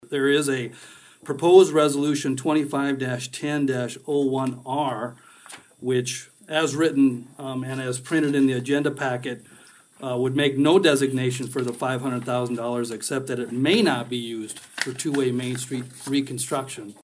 ABERDEEN, S.D (Hub City Radio)- At the Aberdeen, SD City Council meeting earlier this Monday evening, October 6th, the City Council voted unanimously on an 8-0 vote to keep downtown Main Street as a one-way road.
Wager discuss the other resolution dealing with the adjustment for the Capital Improvement Plan.